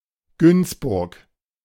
Gunzburgo (en alemán: Günzburg, pronunciación:
De-Günzburg.ogg.mp3